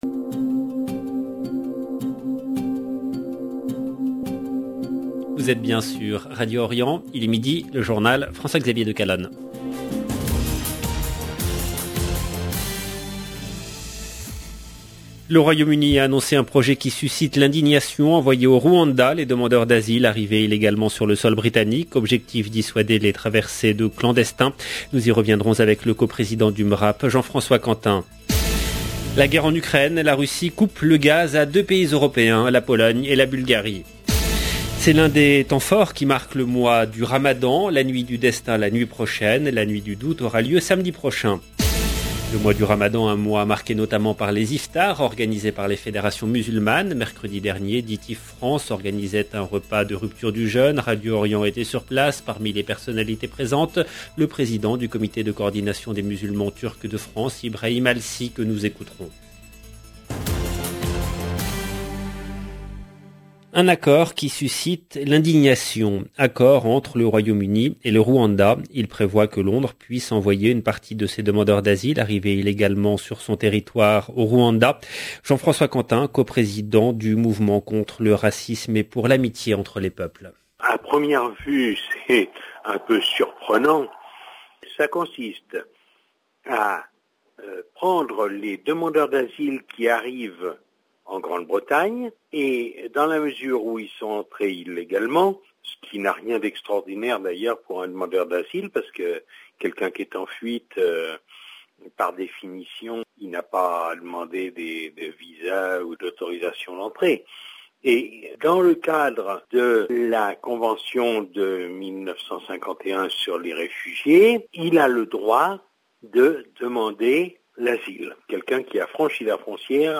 Radio Orient était sur place.